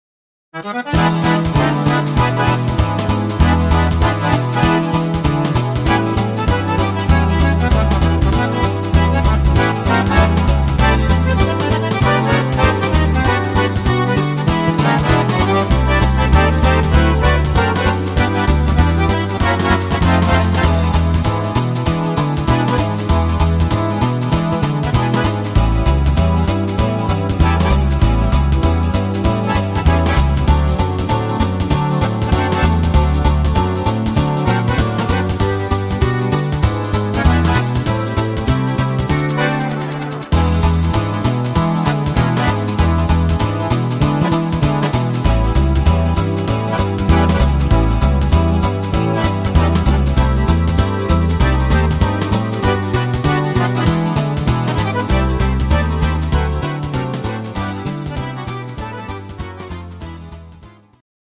Rhythmus  Swingfox
Art  Deutsch, Schlager 60er